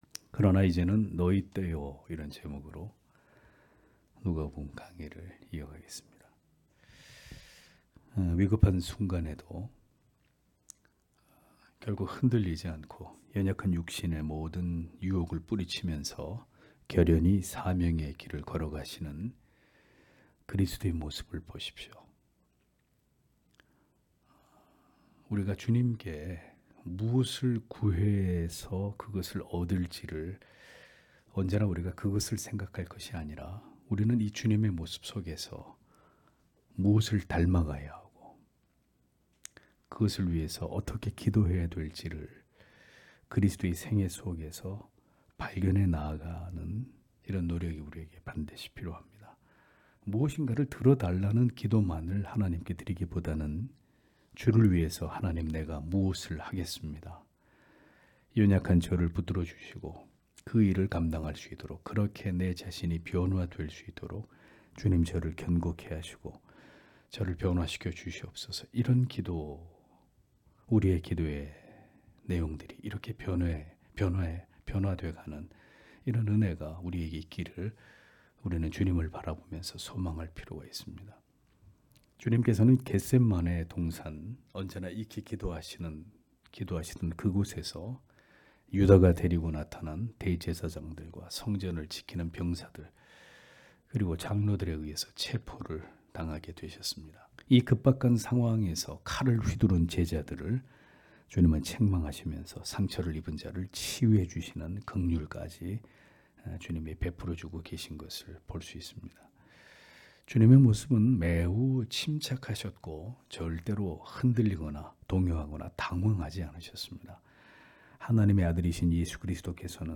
금요기도회 - [누가복음 강해 172] '그러나 이제는 저희 때요' (눅 22장 52- 53절)